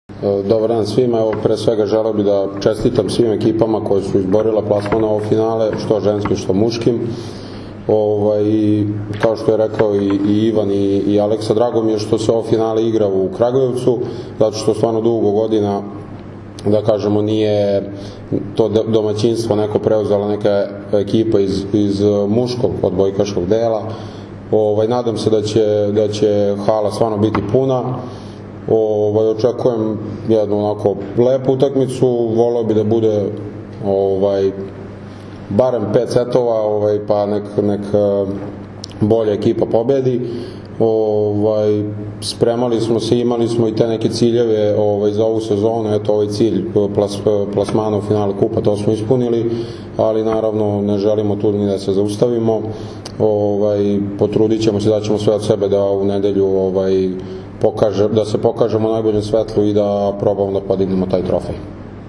U beogradskom hotelu “M” danas je održana konferencija za novinare povodom finalnih utakmica 59. Kupa Srbije 2024/2025. u konkurenciji odbojkašica i jubilarnog, 60. Kupa Srbije 2024/2025. u konkurenciji odbojkaša, koje će se odigrati se u “MTS hali Jezero” u Kragujevcu u nedelju, 23. februara.
Izjava